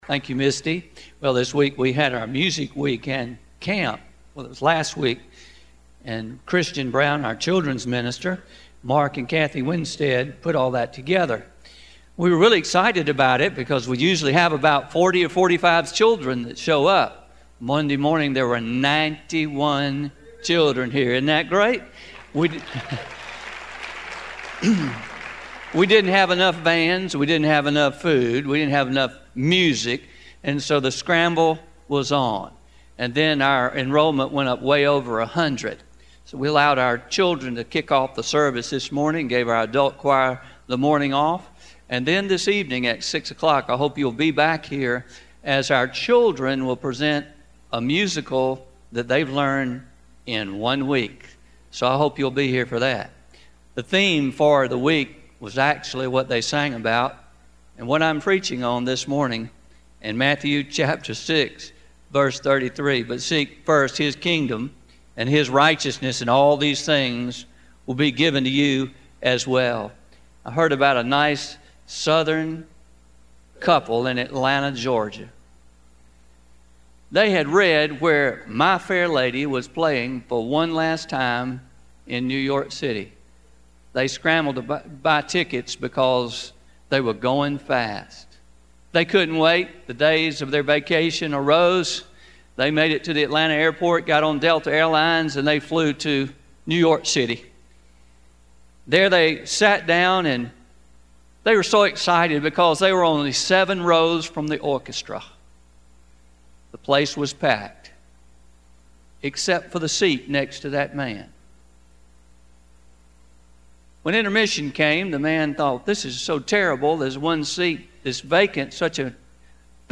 Pre-recorded – The Strength of Priorities